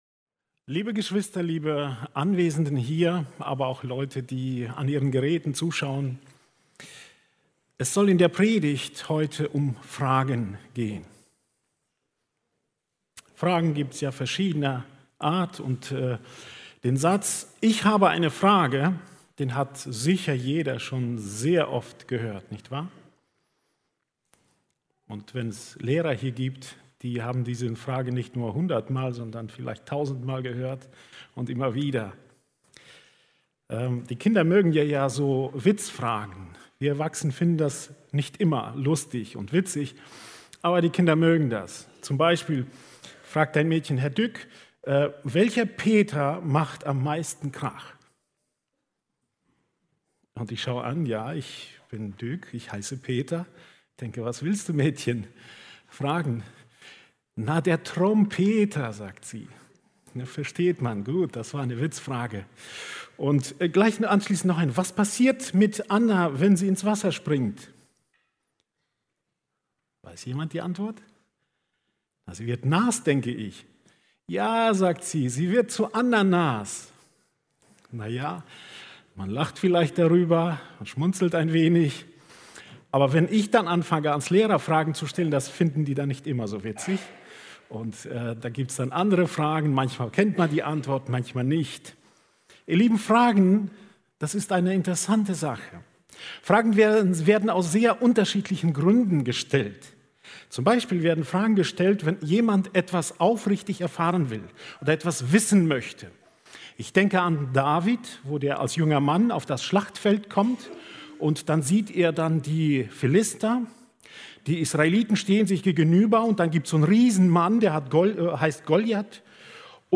April 2022 Jesus stellt zwei lebensentscheidende Fragen Prediger